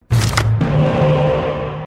game_start.mp3